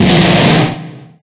Fire4.ogg